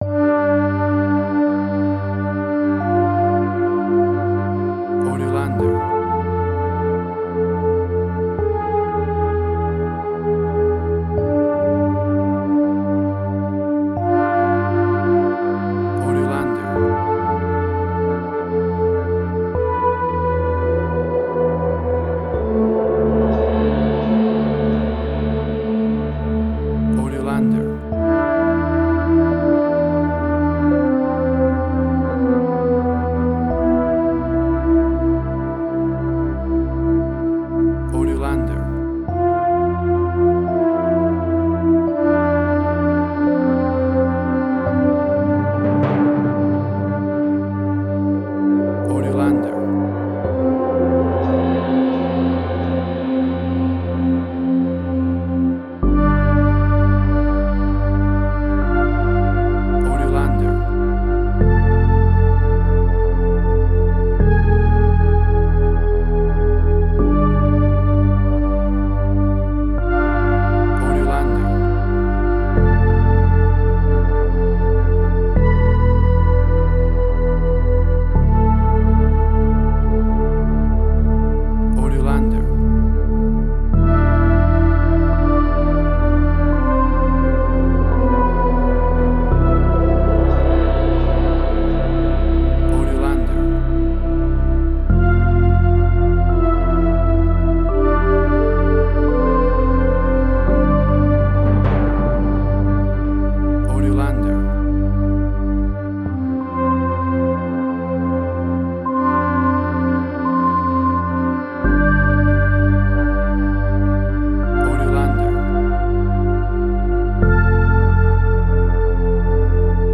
New Age.
WAV Sample Rate: 16-Bit stereo, 44.1 kHz